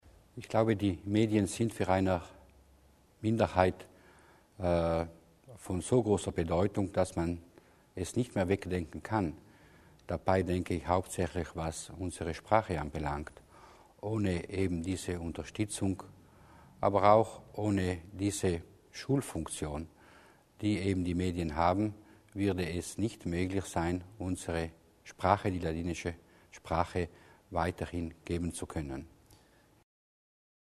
Landeshauptmann Durnwalder zum Minderheitenschutz
LPA - Beim Tag der ladinischen Kultur am heutigen 22. Oktober in Bozen standen Visionen zur Entwicklung der Medien von Minderheiten im Zentrum des Interesses. Landeshauptmann Luis Durnwalder und Ladinerlandesrat Florian Mussner verwiesen auf den großen Wert, den Medien für eine Minderheit und deren Sprache haben.